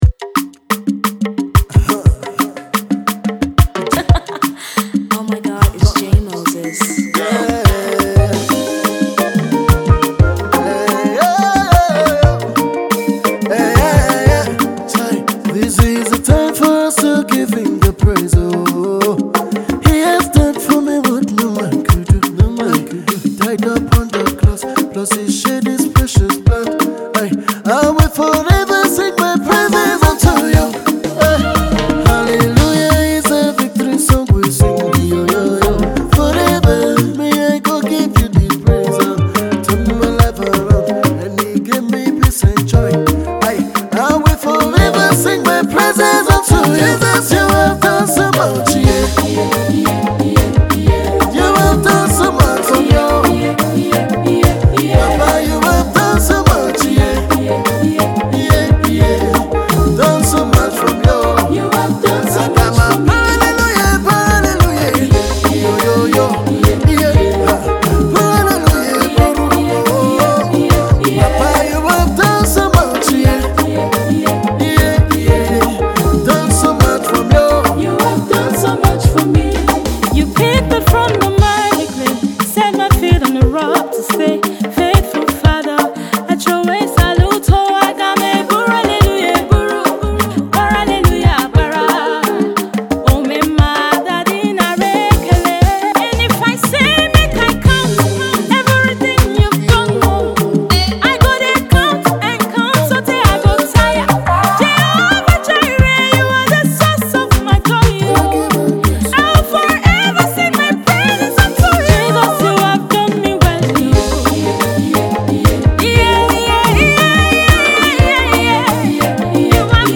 thanksgiving song